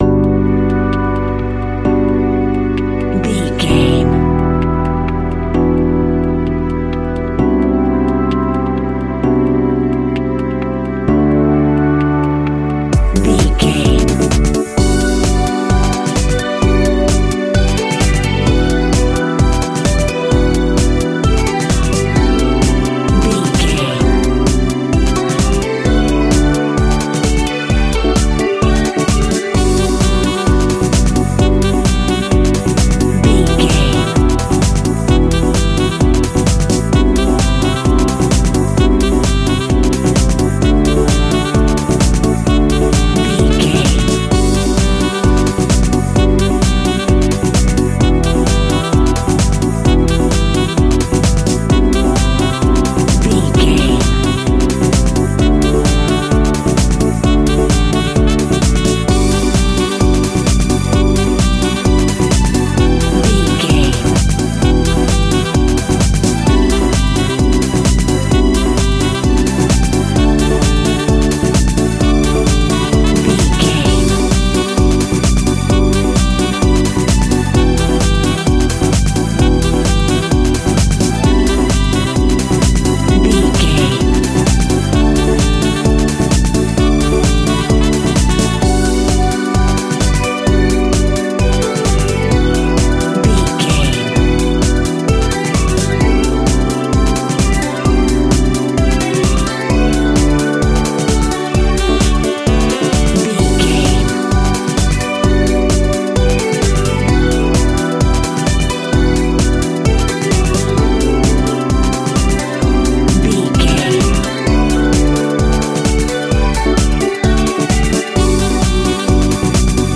Ionian/Major
groovy
uplifting
driving
energetic
electric piano
synthesiser
drum machine
saxophone
strings
deep house
nu disco
upbeat
funky guitar
synth bass